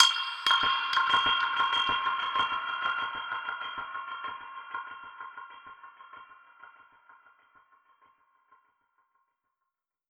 Index of /musicradar/dub-percussion-samples/95bpm
DPFX_PercHit_D_95-08.wav